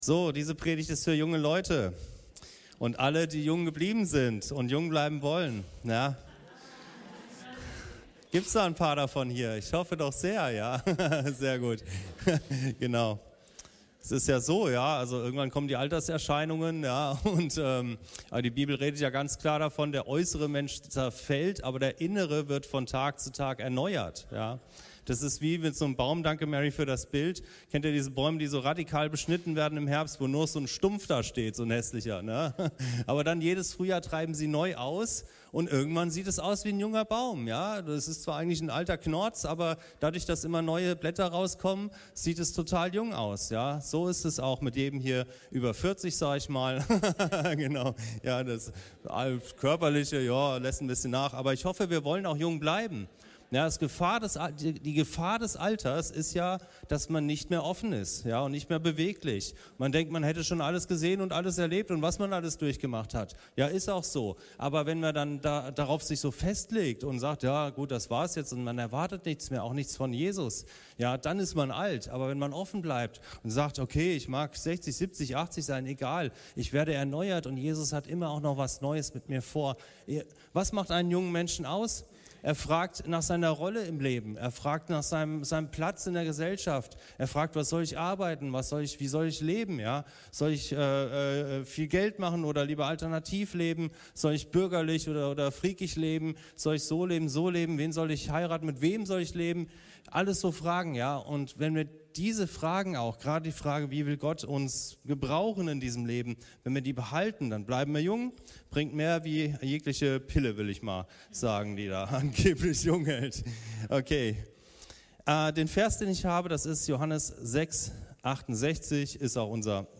Jesus – alternativlos ~ Anskar-Kirche Hamburg- Predigten Podcast